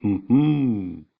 Talking Ben Hmmm